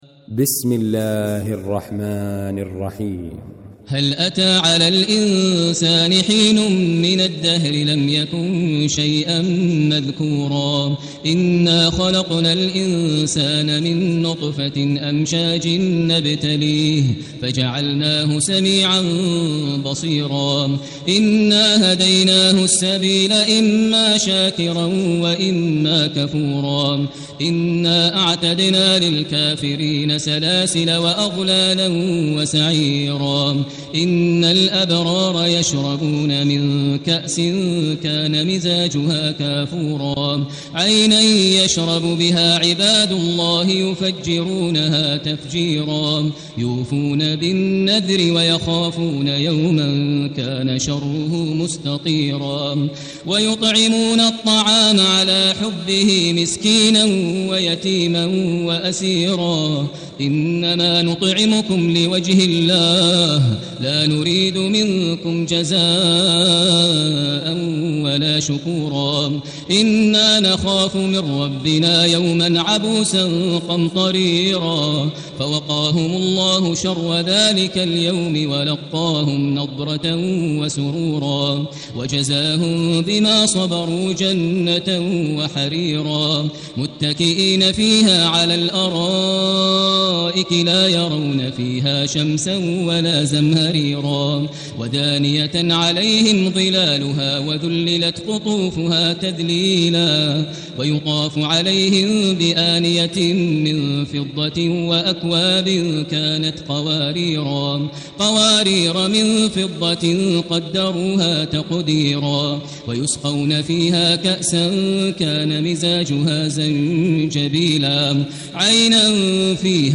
المكان: المسجد الحرام الشيخ: فضيلة الشيخ ماهر المعيقلي فضيلة الشيخ ماهر المعيقلي الإنسان